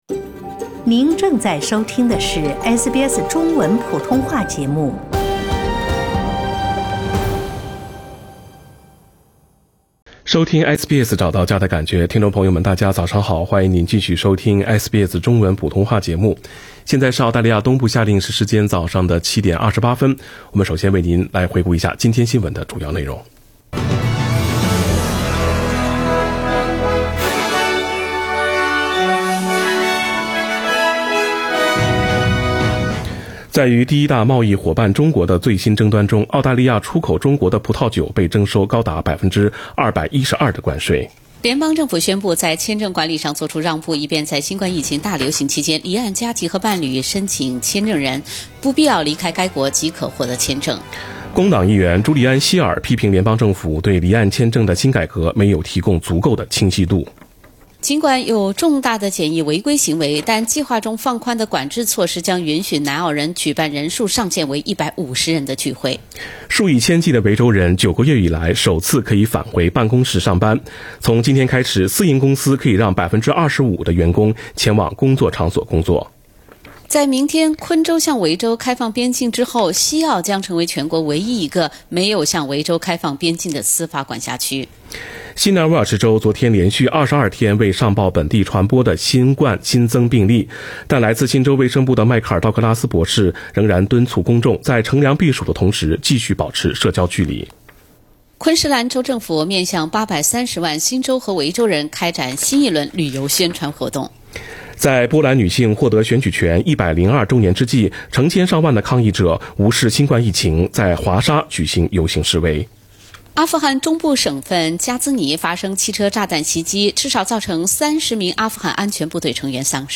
SBS早新聞（11月30日）